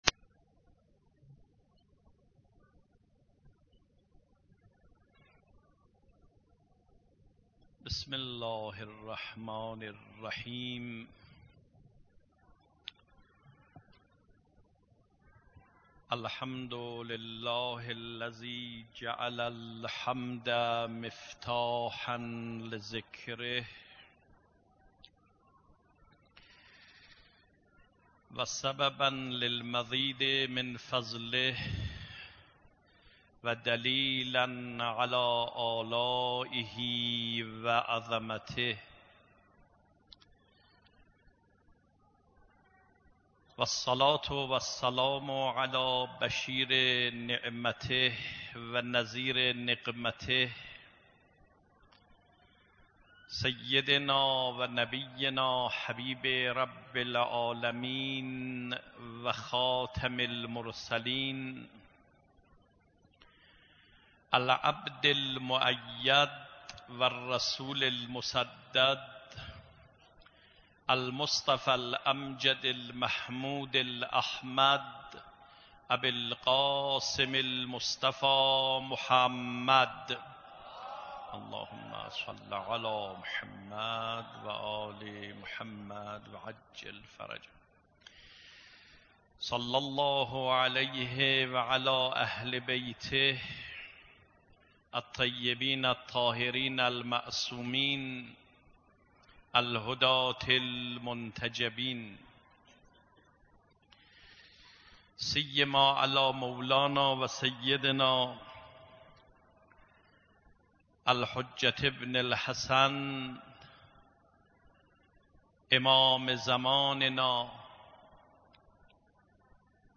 مصلی خاتم الانبیاء احمدآباد - سخنرانی